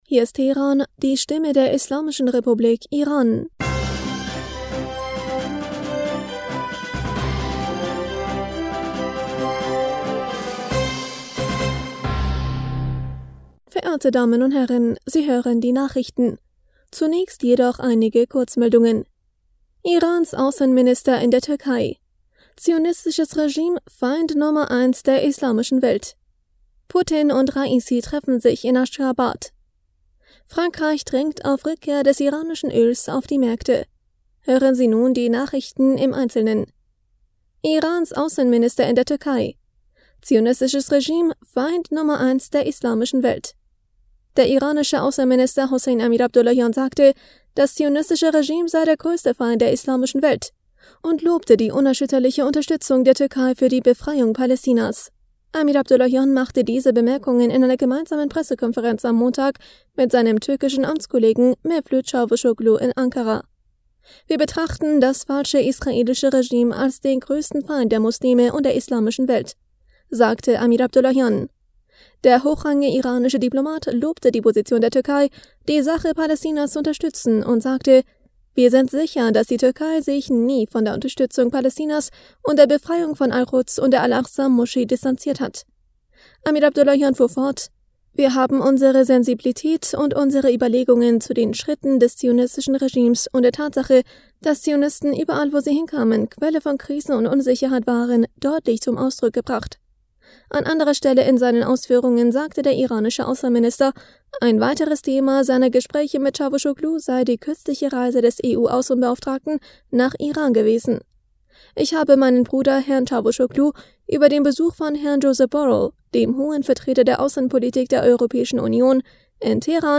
Nachrichten vom 28. Juni 2022
Die Nachrichten von Dienstag dem 28. Juni 2022